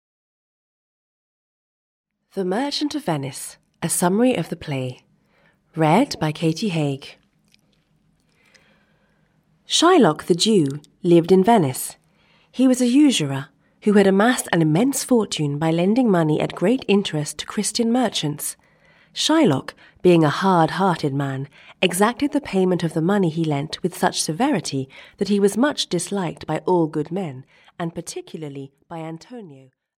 The Merchant of Venice, a Summary of the Play (EN) audiokniha
Ukázka z knihy